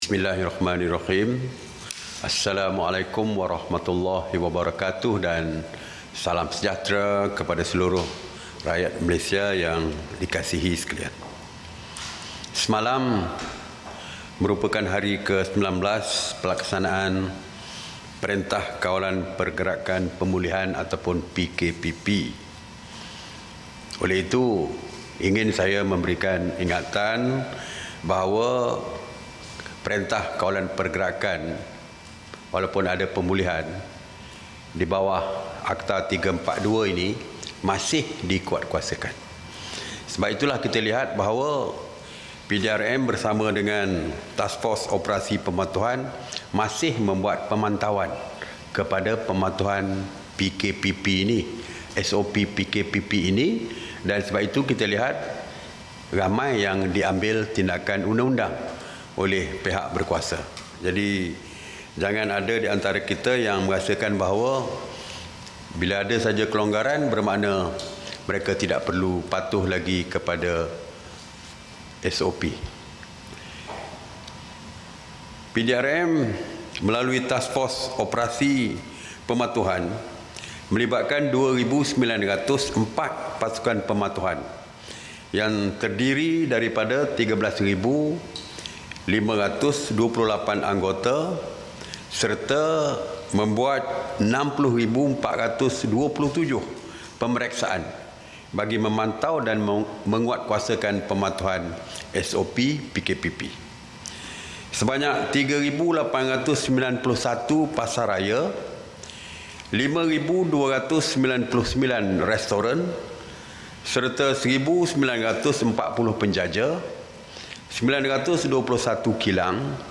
[SIDANG MEDIA] Perkembangan terkini PKPP - 29 Jun 2020
Ikuti sidang media oleh Menteri Kanan Keselamatan, Datuk Seri Ismail Sabri Yaakob berkaitan Perintah Kawalan Pergerakan Pemulihan, PKPP.